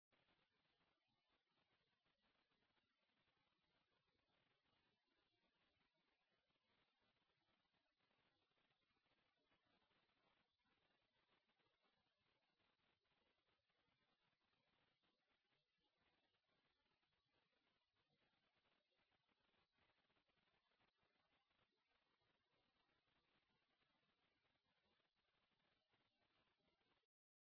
ambience_appartment.mp3